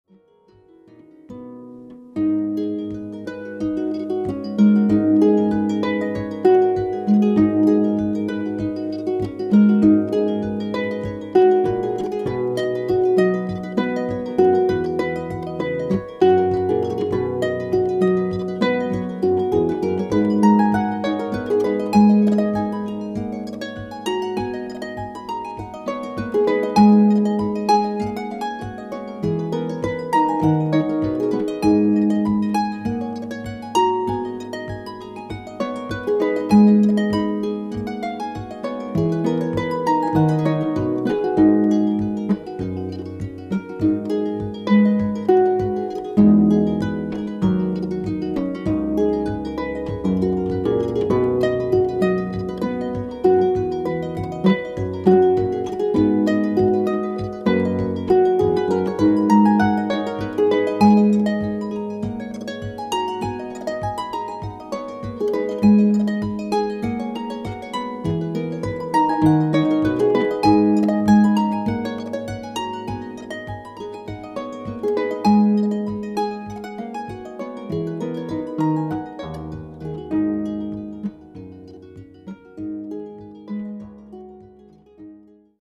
Your feet will be dancing ...
Solo Irish Harp Recording